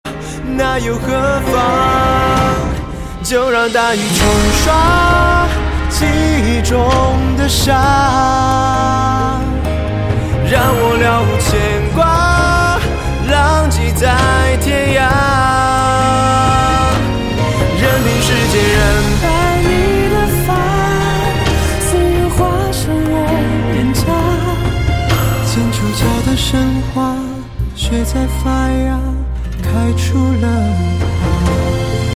Thể loại nhạc chuông: Nhạc trung hoa